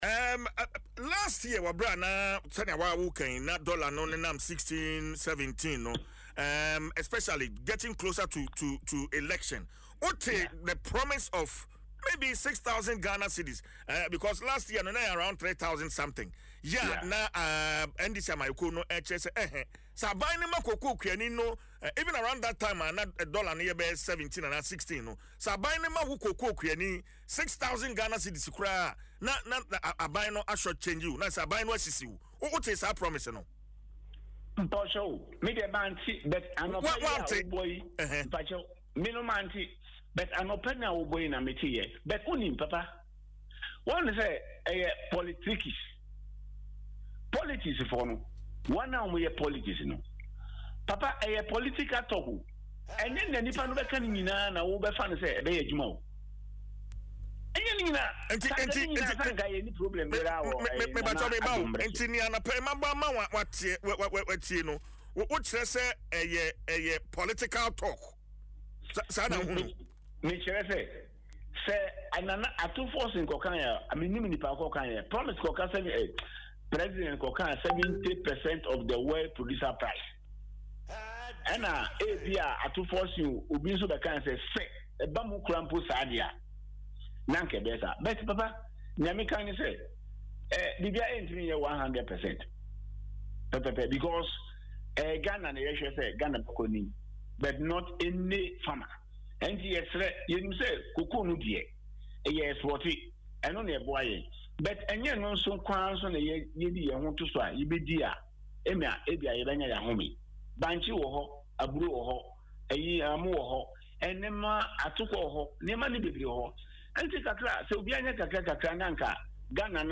Speaking on Adom FM’s Dwaso Nsem